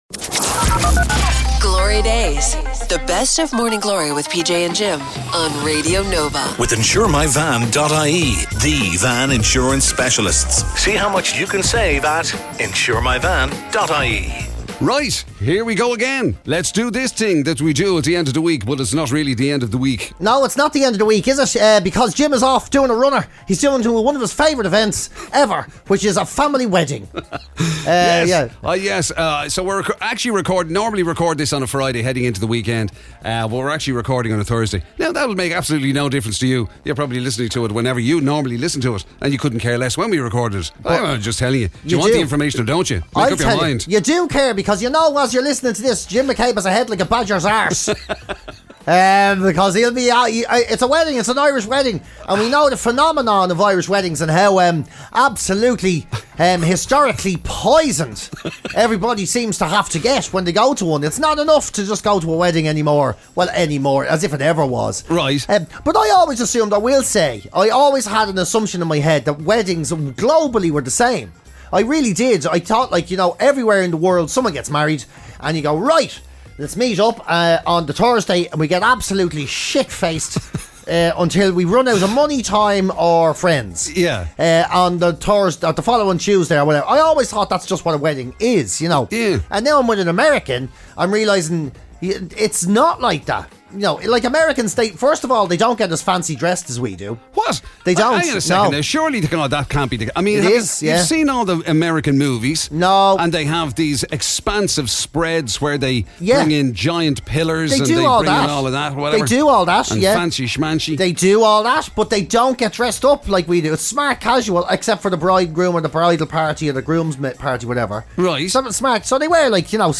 Warning this series (unlike the radio show!) contains explicit language that may be offensive to some listeners.
… continue reading 252 ตอน # Comedy # Radio Nova